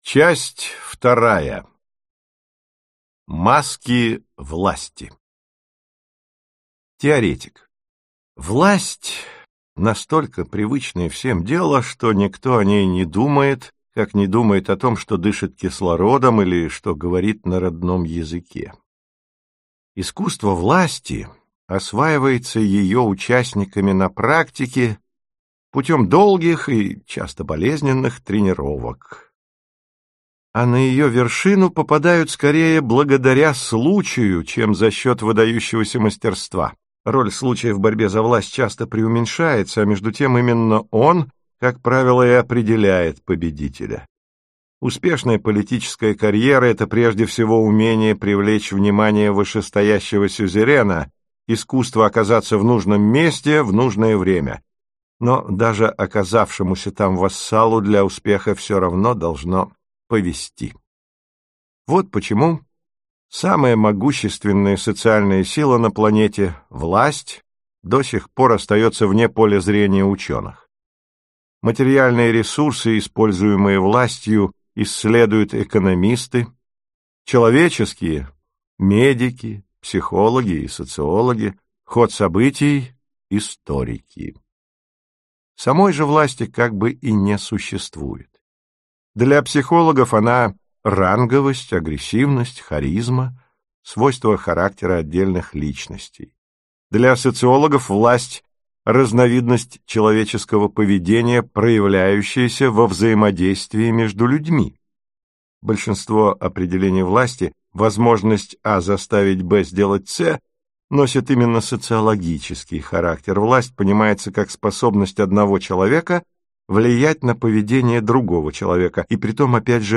Аудиокнига Лестница в небо. Диалоги о власти, карьере и мировой элите. Часть 3 | Библиотека аудиокниг